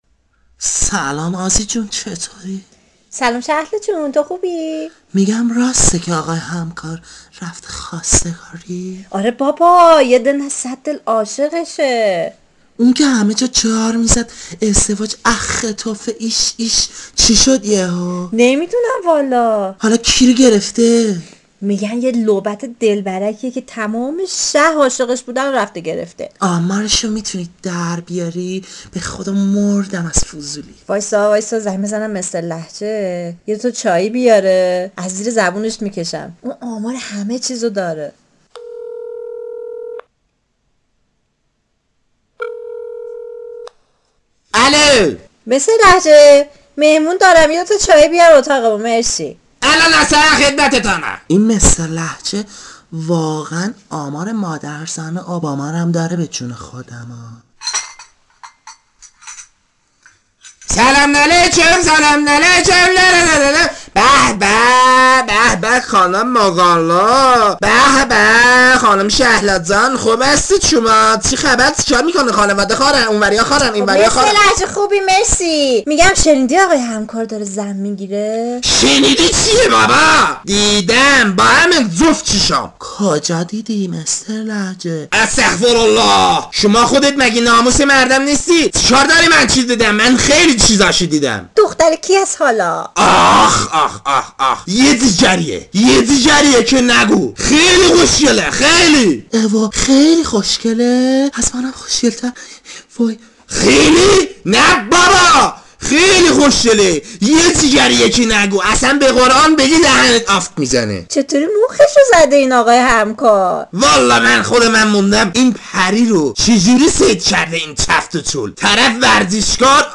صداپیشگان: